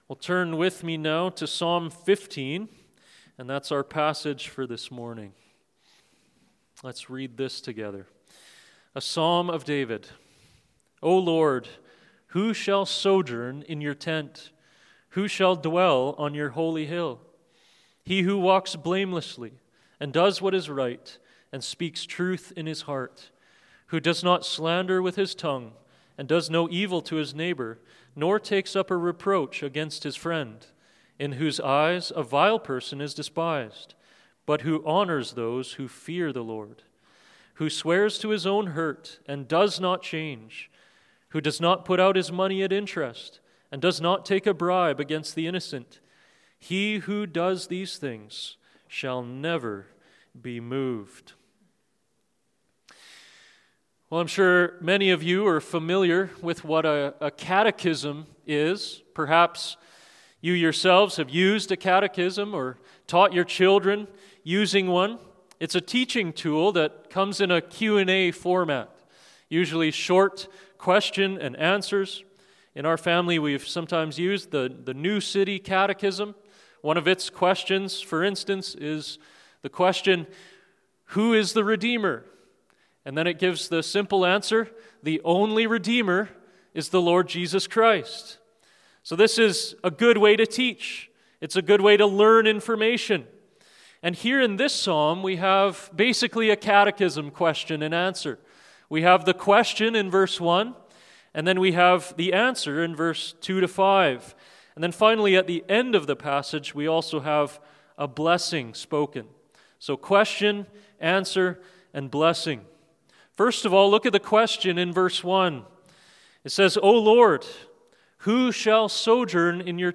From Series: "Stand Alone Sermons"